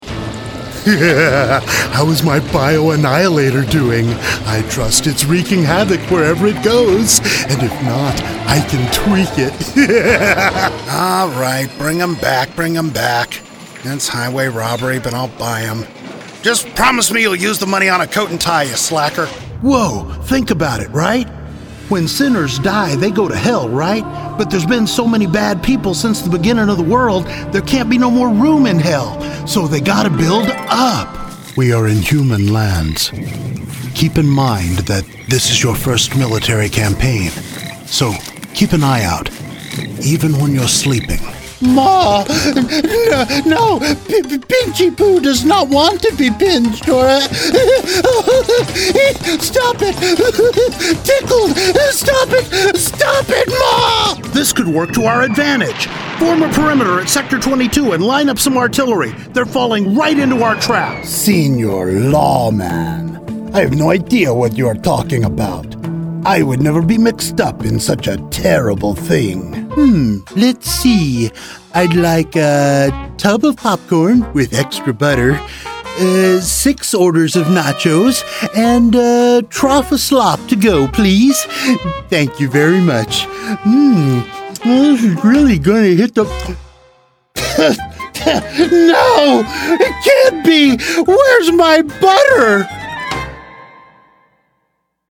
CHARACTER DEMO (:90)
It’s an entertaining sampling of my character stylings for animation and video games. Not an exhaustive catalog, by any means — just a few of the voices inside my head.